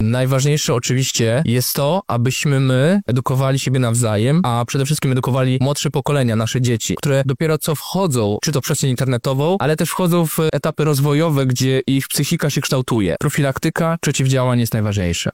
W sobotę, 8 marca na Wydziale Politologii i Dziennikarstwa UMCS odbyła się debata na temat hejtu w sieci, w dobie rozwoju sztucznej inteligencji.